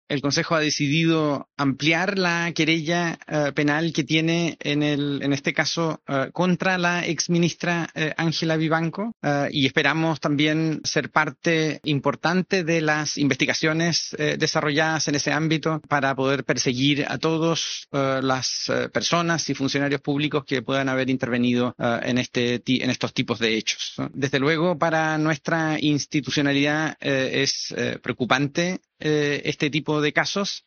El presidente del Organismo, Raúl Letelier, explicó la decisión de ampliar la querella.